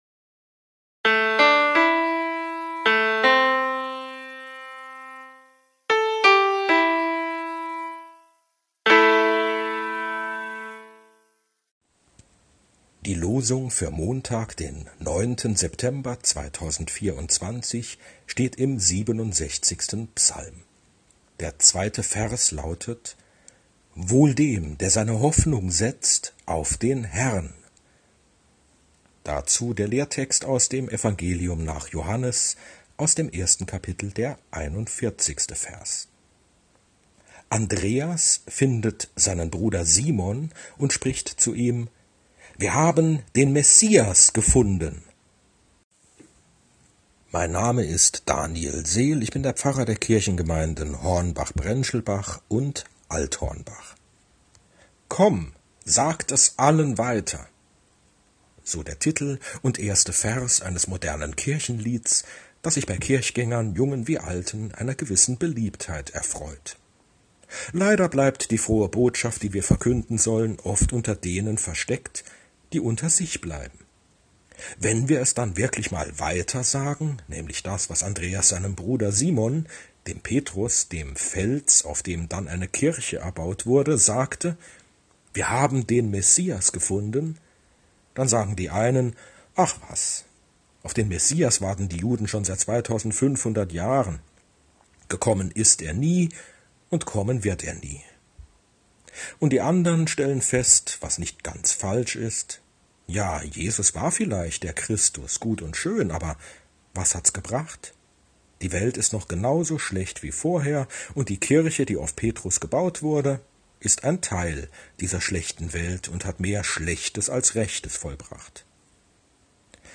Losungsandacht für Montag, 09.09.2024